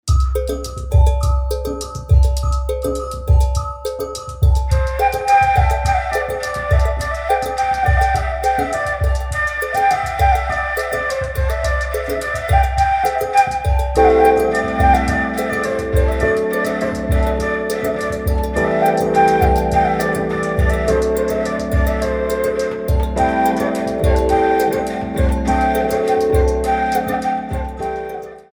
four beats